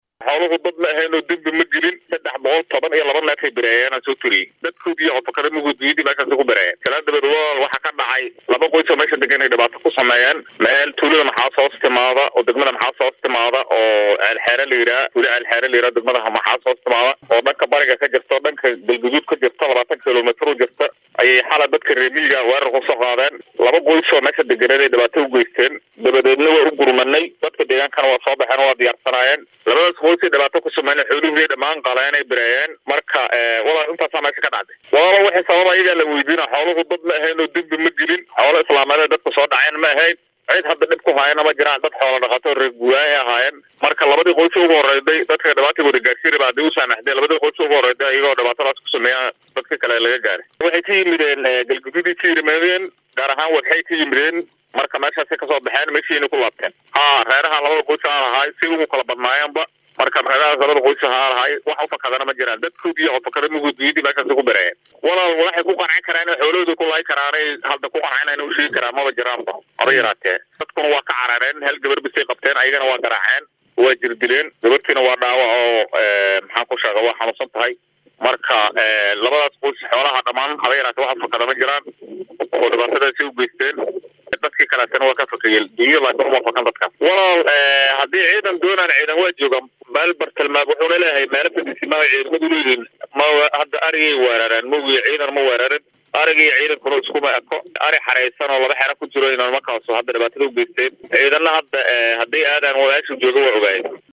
Guddoomiyaha degmada Maxaas Muumi Maxamad Xalane ayaa warbaahinta Soomaaliya uga warramay xoolaha ay Al-Shabaab ku bireeyeen deegaan hoos yimaada degmadaasi.
Guddoomiyaha-Maxaas.mp3